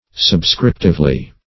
subscriptively - definition of subscriptively - synonyms, pronunciation, spelling from Free Dictionary
-- Sub*scrip"tive*ly , adv.